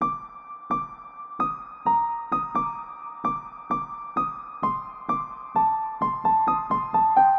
GD2GO小鼓和其他东西
Tag: 140 bpm Trap Loops Drum Loops 2.31 MB wav Key : Unknown